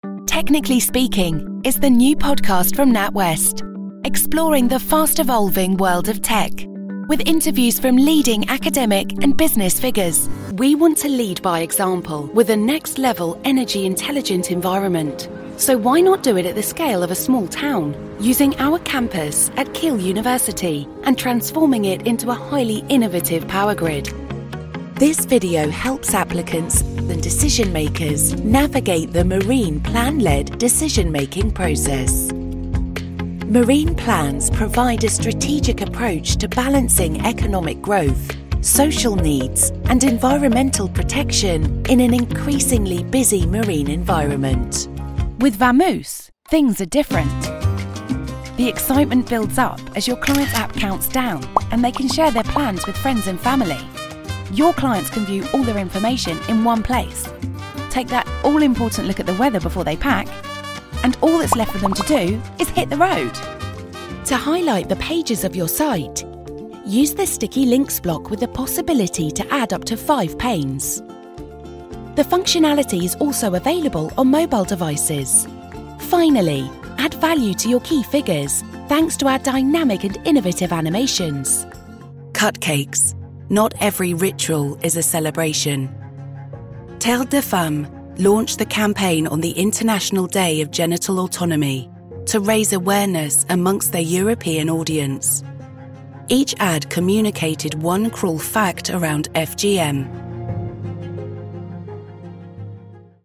Corporate Showreel
Her tone is warm and dynamic with a hint of gravitas. She has a clear, confident and relatable delivery.
Female
British RP
Neutral British
Confident